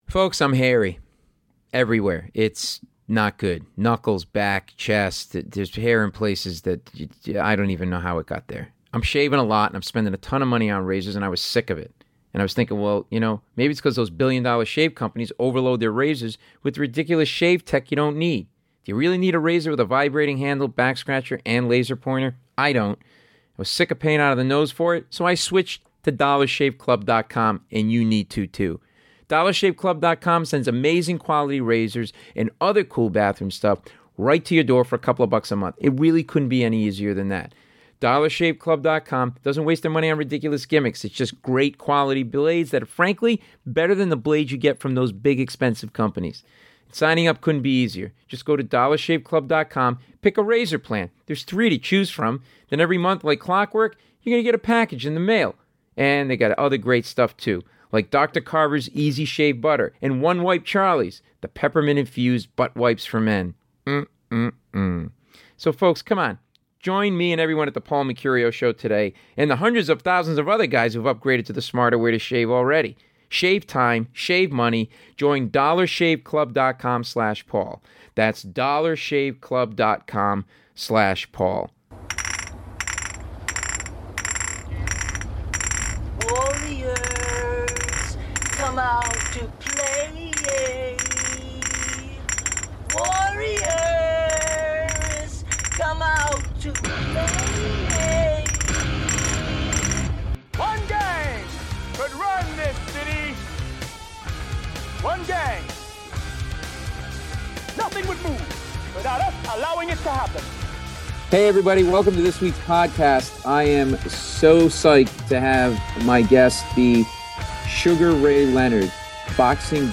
I sit down with one of my idols growing up, the Great Sugar Ray for a fascinating talk about why a person boxes, how he preps for a fight, the psychology behind the sport and his historic fights with Roberto Duran, Marvin Hagler and Tommy Hearns. Ray is amazing in this interview, giving honest insight into the world of boxing, himself and what makes a champion tick.